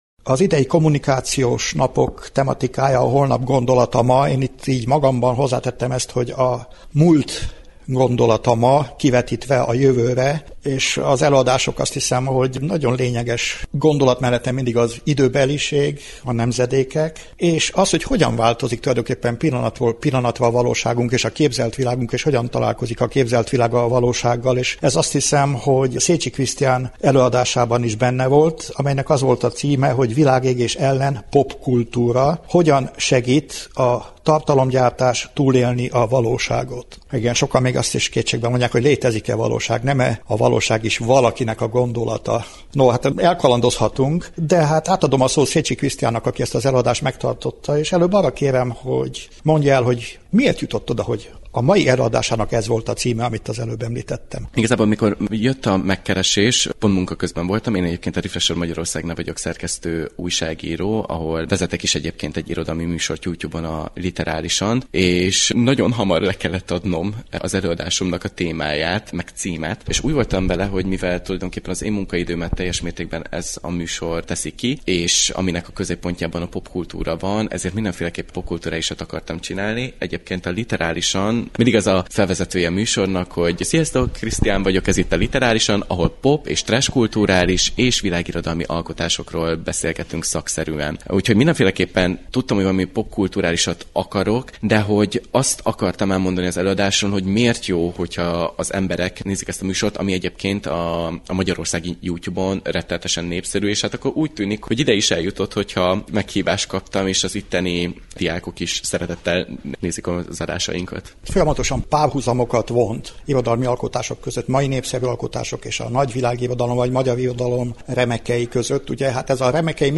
Összeállításunkban négy, a munkálatok során készült beszélgetés hangzik el.